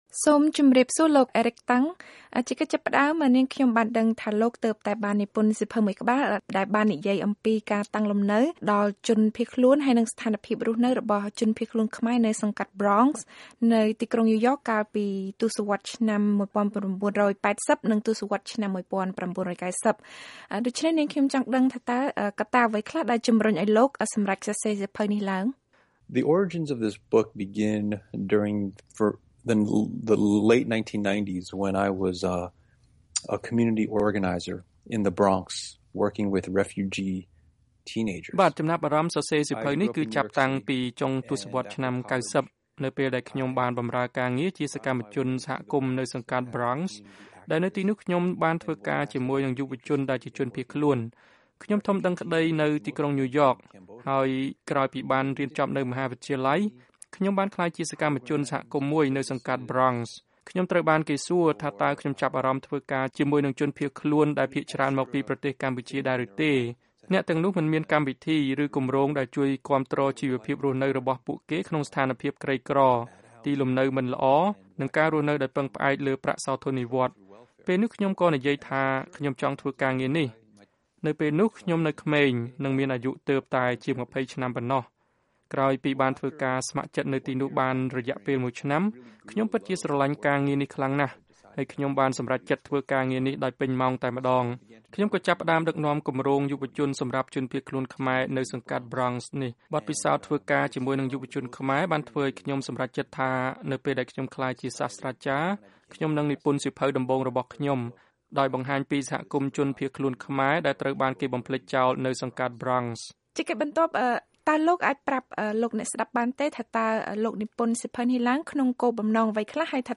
បទសម្ភាសន៍ VOA៖ ការតស៊ូរបស់ជនភៀសខ្លួនខ្មែរនៅតំបន់ក្រីក្រសង្កាត់ Bronx នៅទីក្រុងញូវយ៉ក ក្នុងអំឡុងទសវត្ស១៩៨០ និង១៩៩០